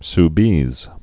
(s-bēz)